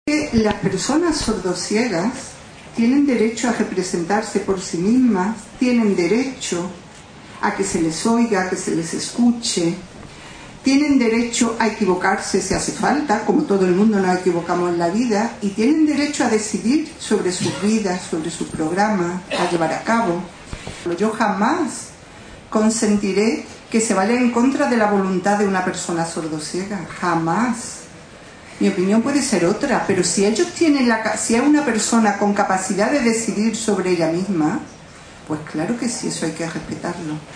El acto central se celebró en el Complejo Deportivo y Cultural de la ONCE en Madrid, con el lema “Caminando sin barreras”.